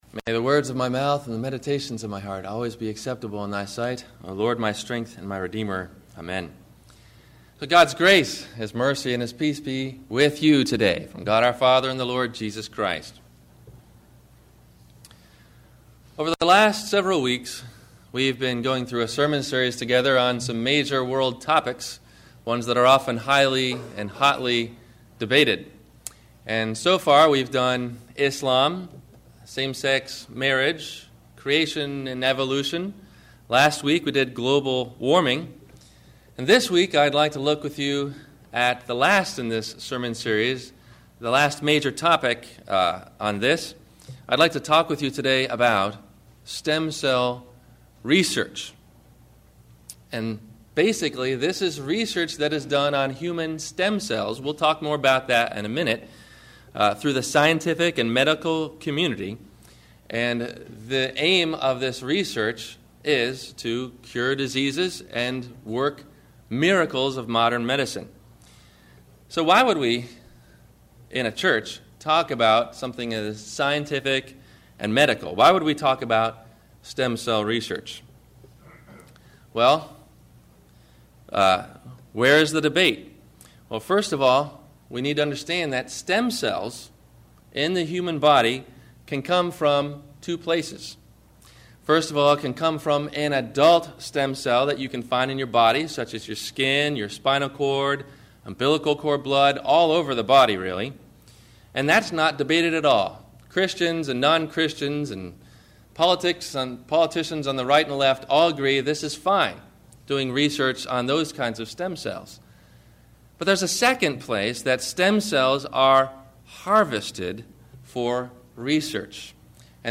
Stem-Cell Research – Sermon – March 09 2008